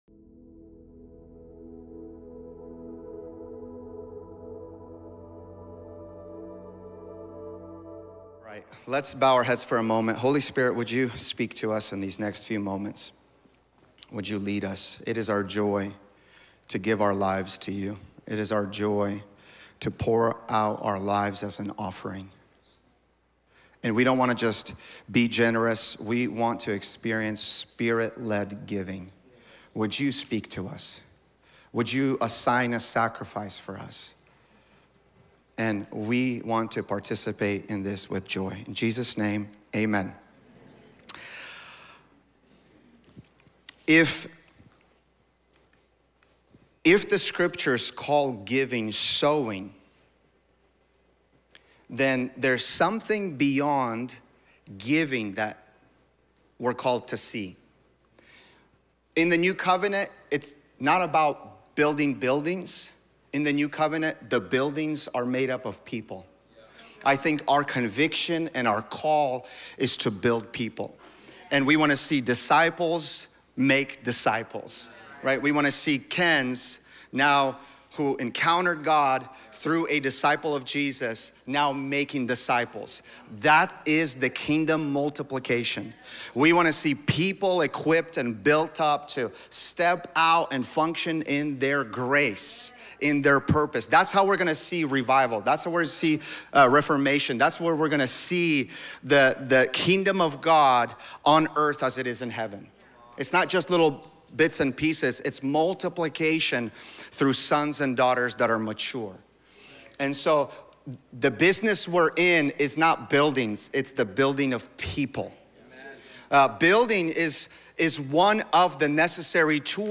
This sermon is Part 4 of our generosity series.